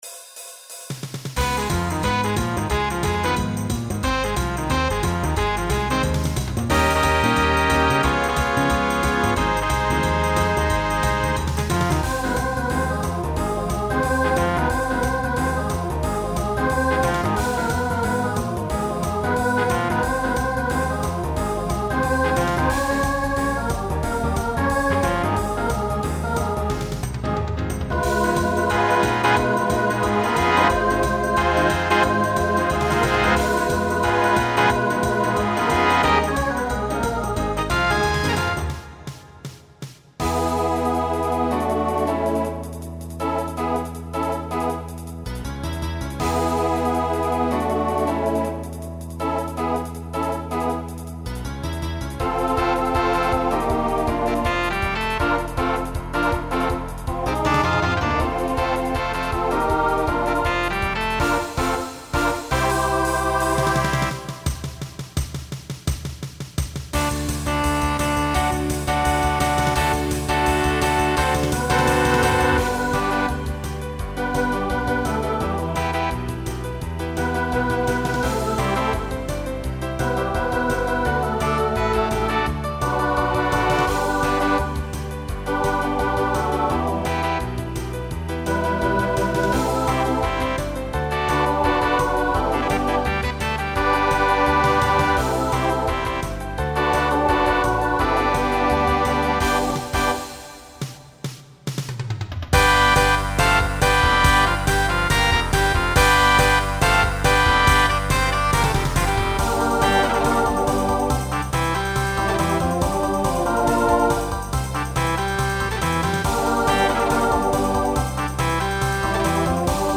Genre Broadway/Film , Rock , Swing/Jazz
Voicing SAB